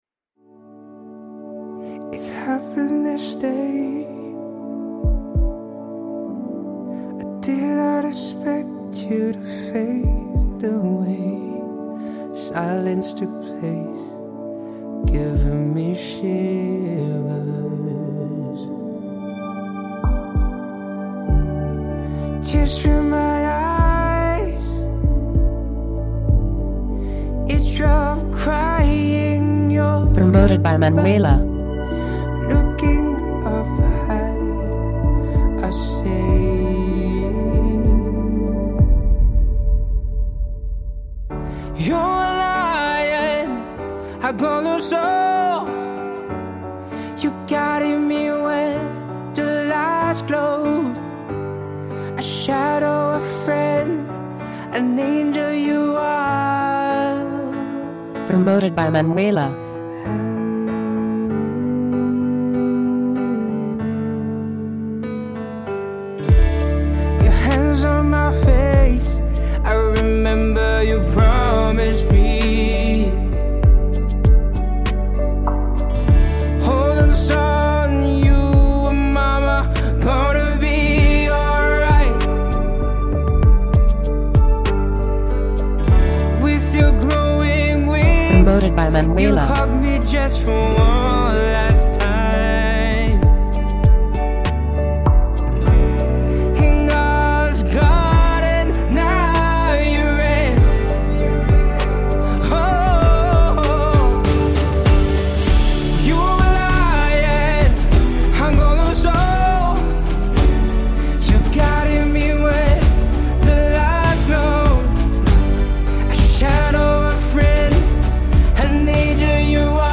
morceau pop
Radio Edit